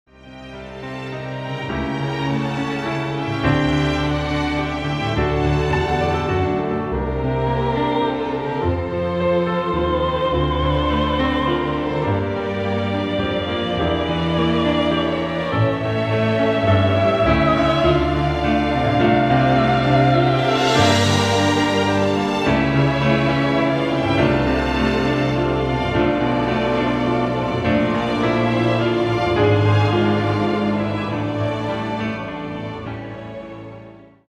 • Качество: 192, Stereo
спокойные
без слов
инструментальные
пианино